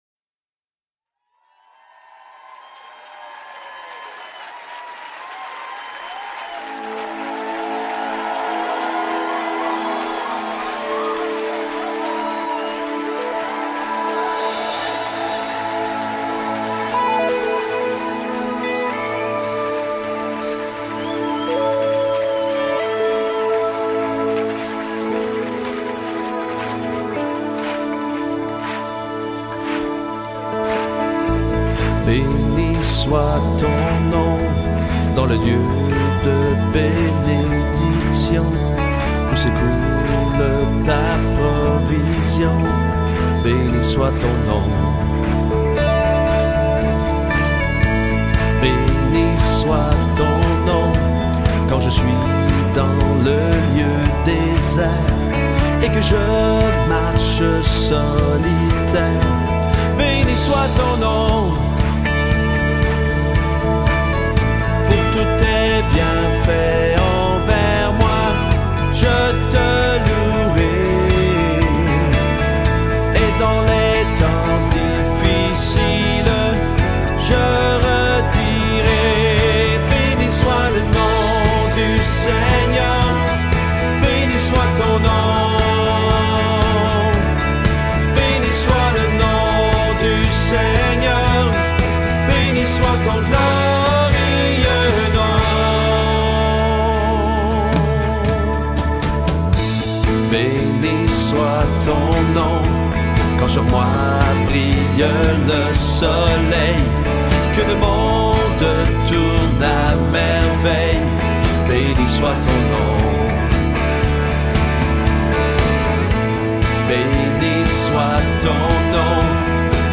49 просмотров 52 прослушивания 1 скачиваний BPM: 124